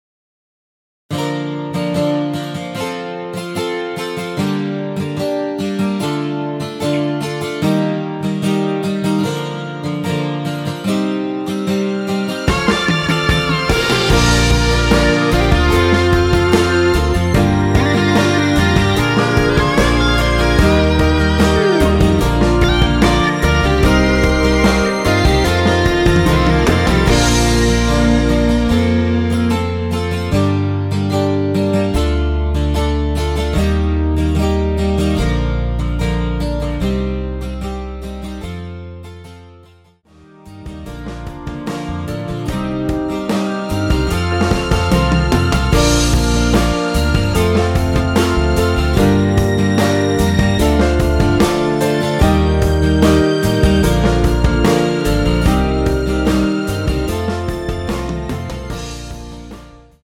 원키에서(+3)올린 MR입니다.
Bb
앞부분30초, 뒷부분30초씩 편집해서 올려 드리고 있습니다.